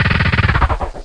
DRILL3.mp3